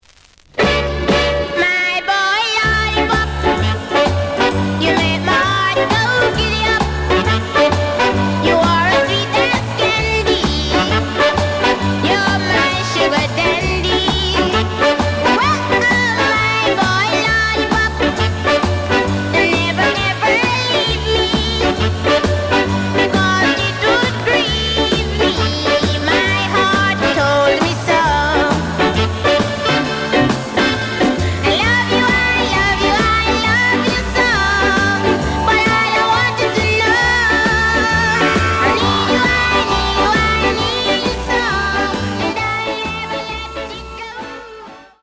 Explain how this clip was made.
The audio sample is recorded from the actual item. ●Format: 7 inch